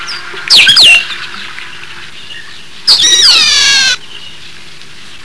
Una de ellas parece ser el Trile (Agelaius thilius)(canto:
c_trile.wav